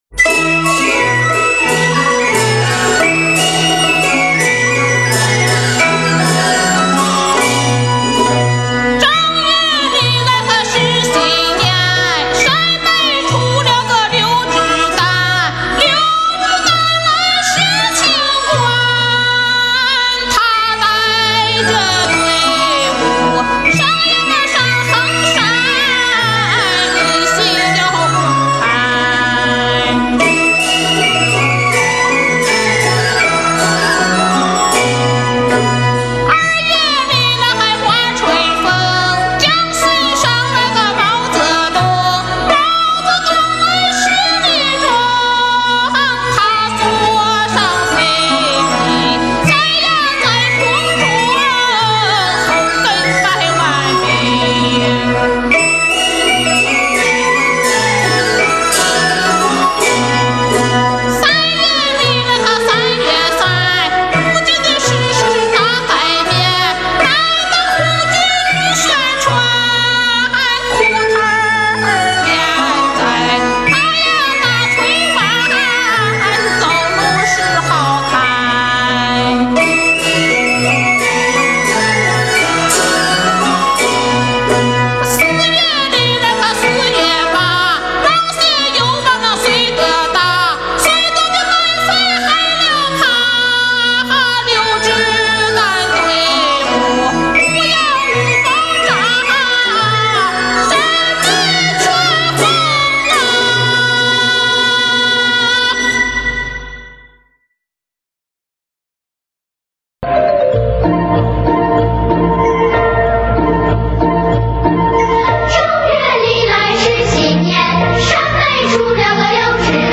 陝北民歌